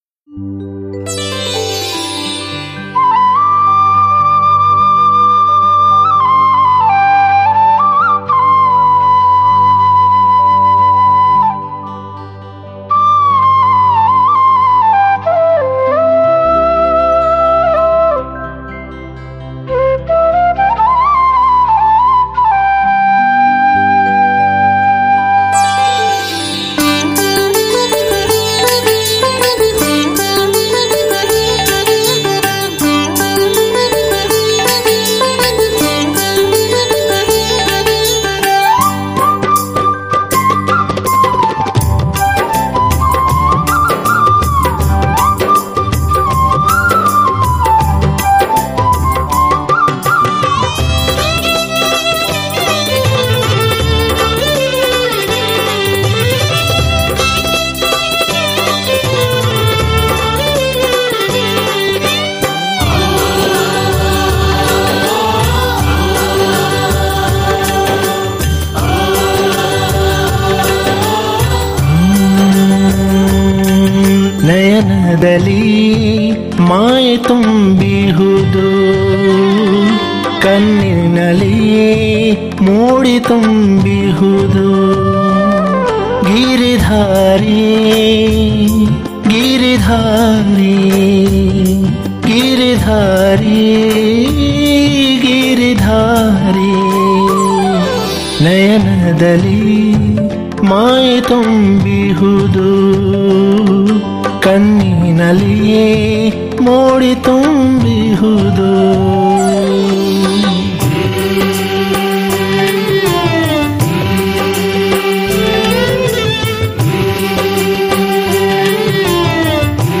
07-Nayanadali-Maye-Tumbihudu1-swaminarayan-kirtan.mp3